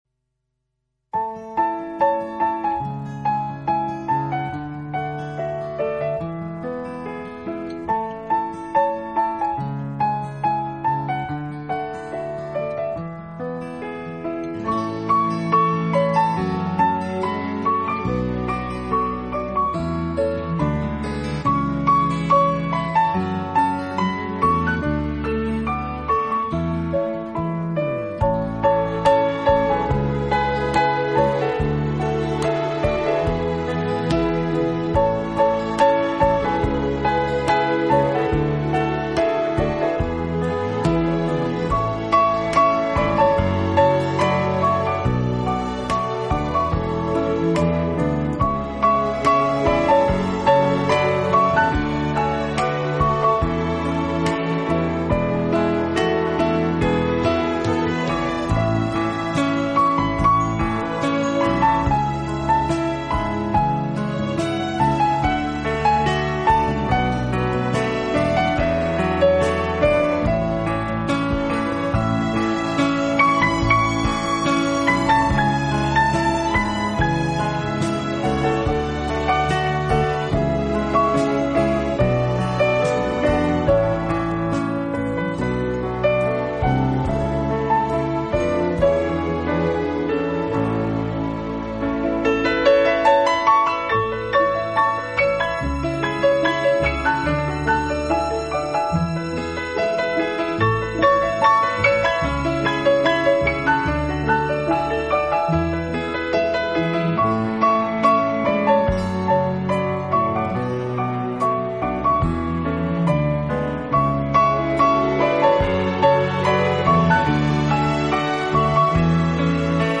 唱片音色优美，质地精良，为音乐爱好者收藏之珍品。